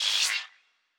pgs/Assets/Audio/Sci-Fi Sounds/Electric/Data Calculating 4_1.wav at master
Data Calculating 4_1.wav